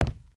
sounds / material / human / step / wood01gr.ogg
wood01gr.ogg